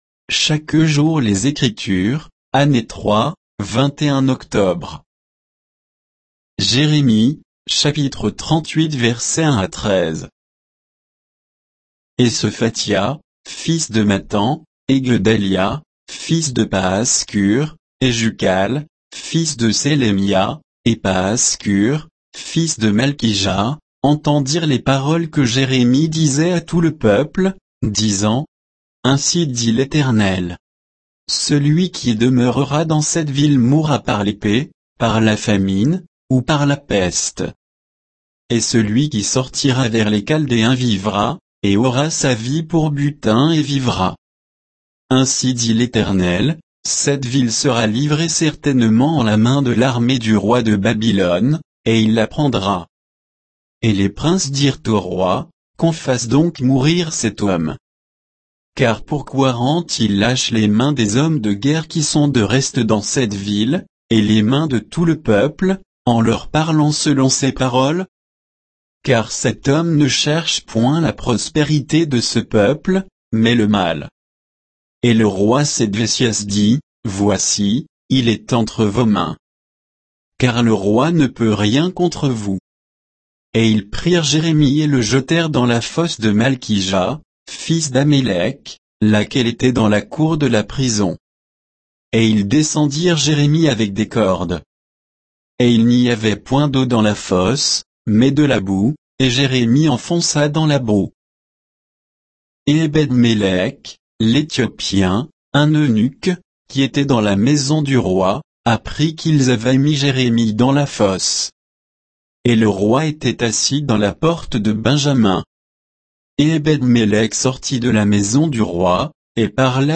Méditation quoditienne de Chaque jour les Écritures sur Jérémie 38